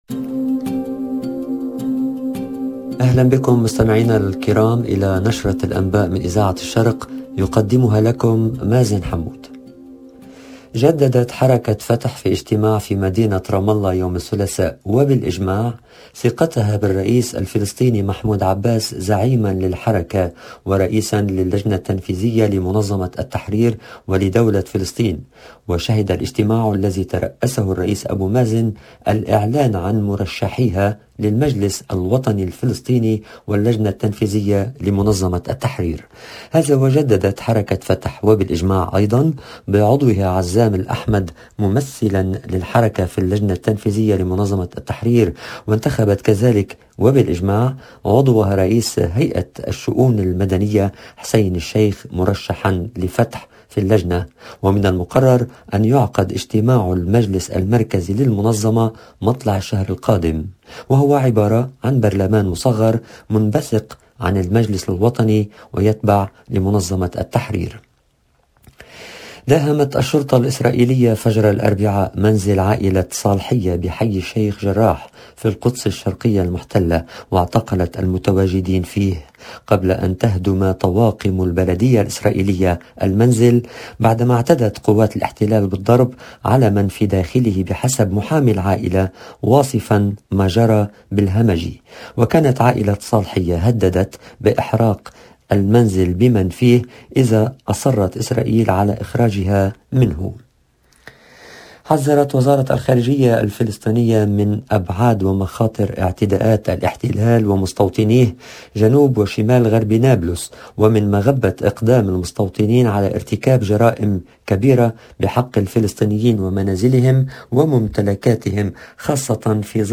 LE JOURNAL DU SOIR EN LANGUE ARABE DU 19/01/22